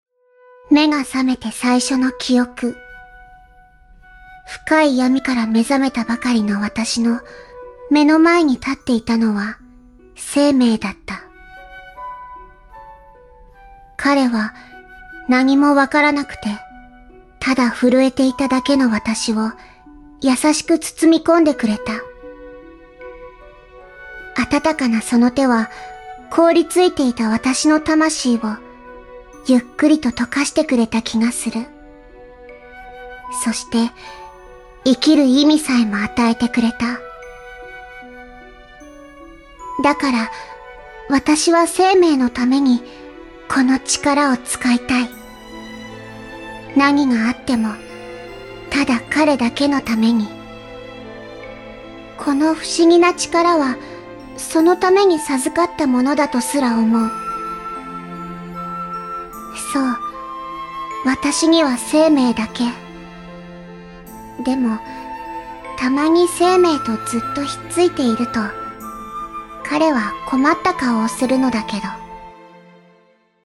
神乐传记1日文原声录音